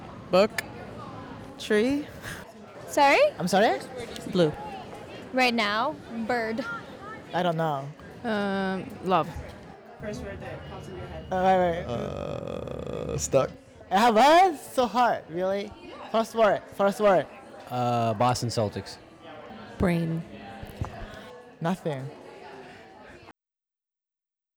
We went to Washington Square Park and got a few (dare I say) excellent recordings.
The brain track showcases the one-word responses, illustrating the idea of what’s at the forefront of one’s mind.